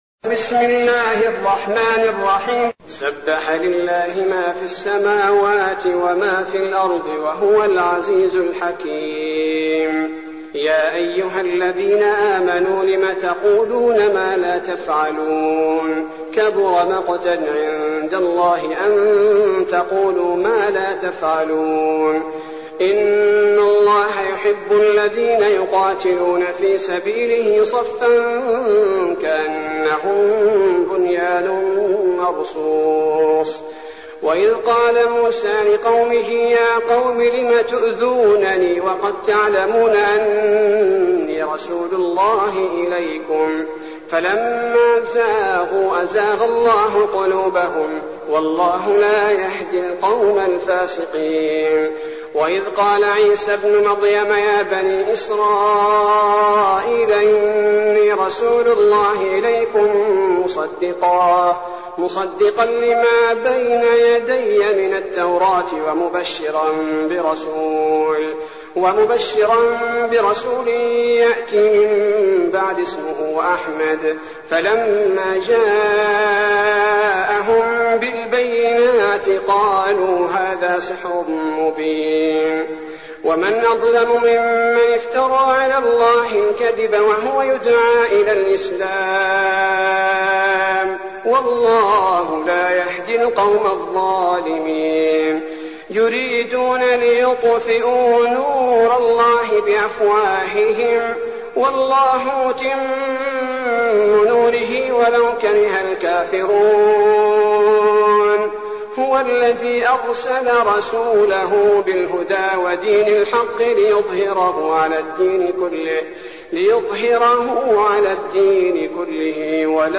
المكان: المسجد النبوي الشيخ: فضيلة الشيخ عبدالباري الثبيتي فضيلة الشيخ عبدالباري الثبيتي الصف